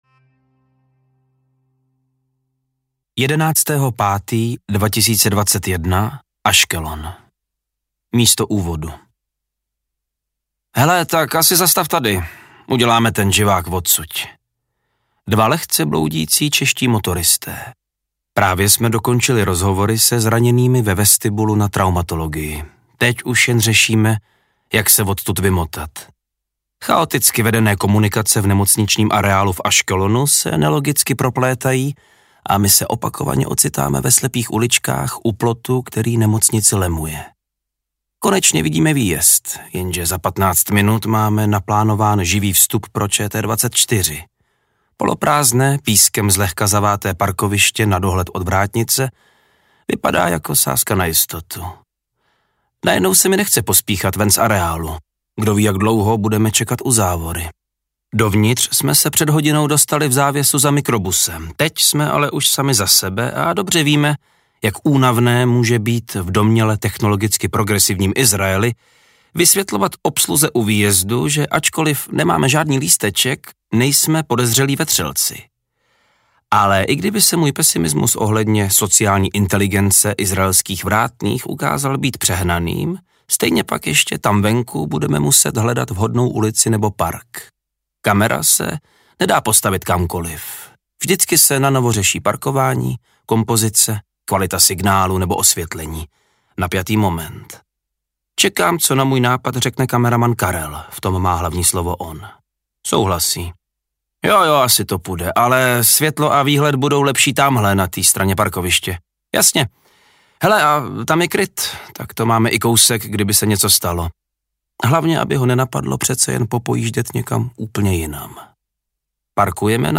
Moje roky v Izraeli audiokniha
Ukázka z knihy
• InterpretVáclav Neužil